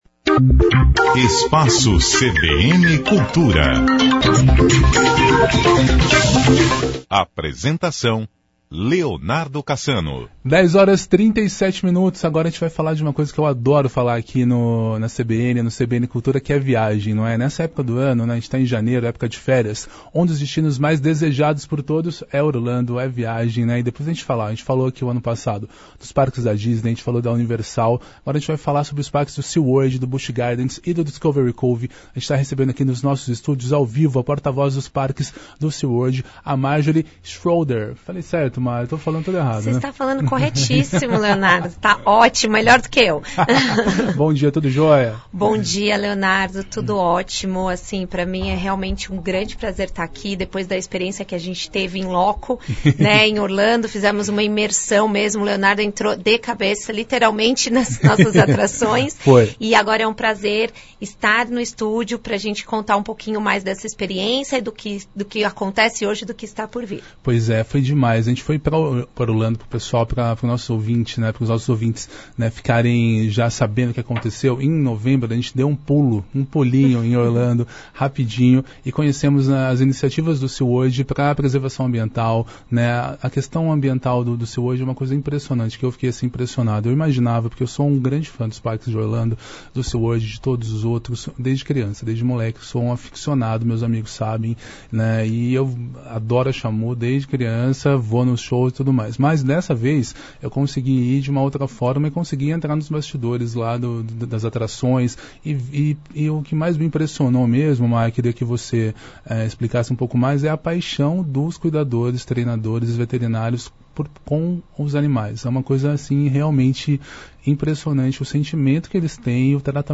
Entrevista: toda a emoção dos parques Sea World, Discovery Cove e Busch Gardens - CBN Campinas 99,1 FM